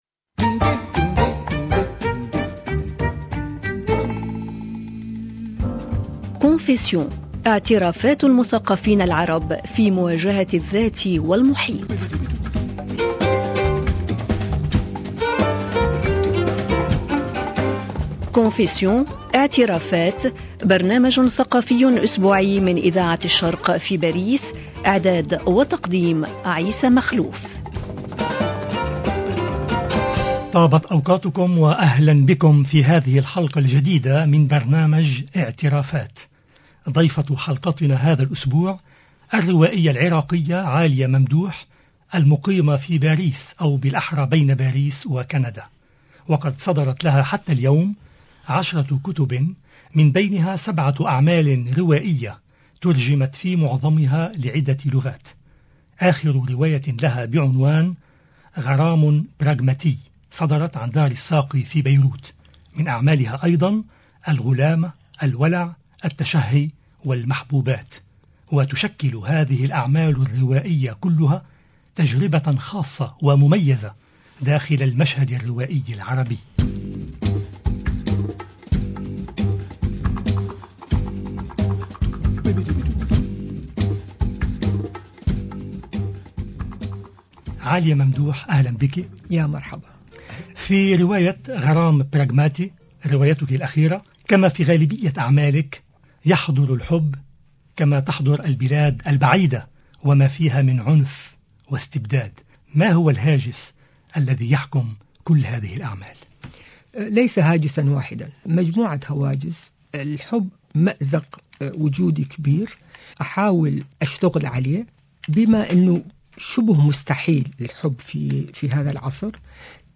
حوار مع اذاعة الشرق